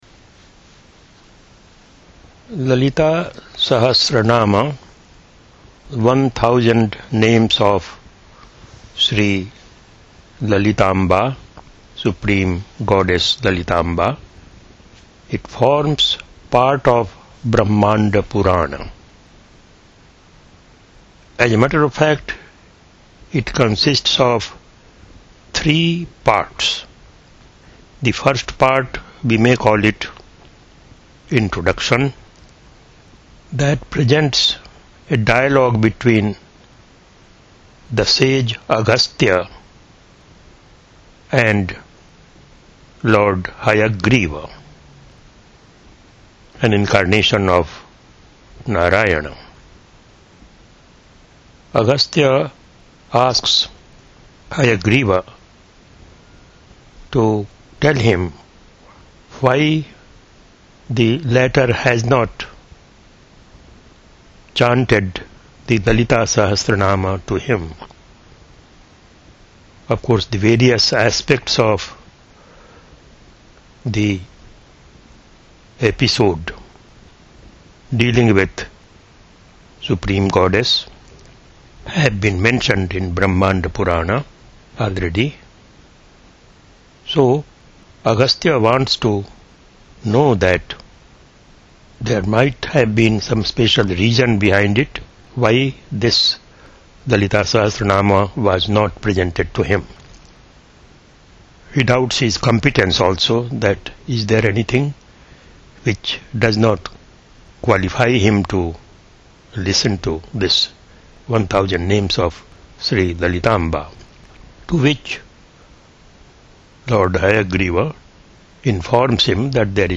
01-lalita_sahasranama_introduction.mp3